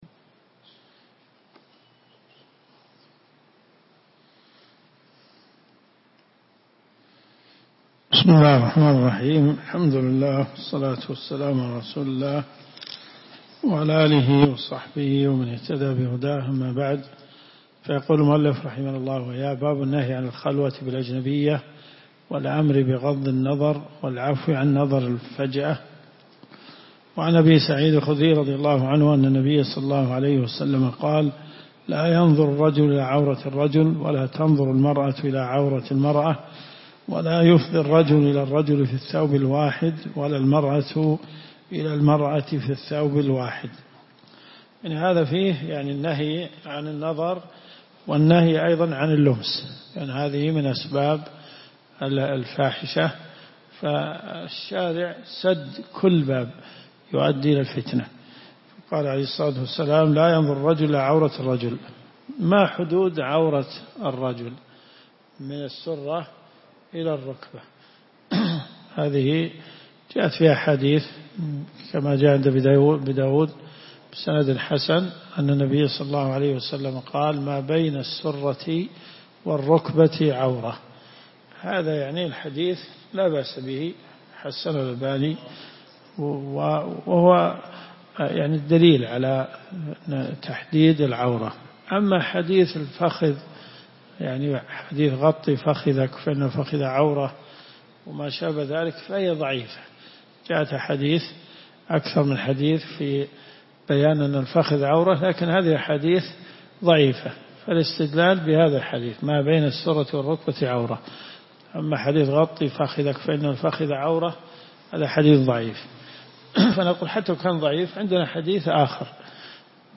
دروس صوتيه ومرئية تقام في جامع الحمدان بالرياض - فتاوى .